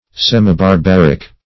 Search Result for " semibarbaric" : The Collaborative International Dictionary of English v.0.48: Semibarbaric \Sem`i*bar*bar"ic\, a. Half barbarous or uncivilized; as, semibarbaric display.
semibarbaric.mp3